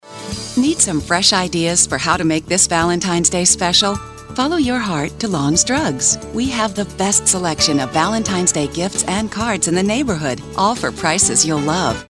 Commercial 1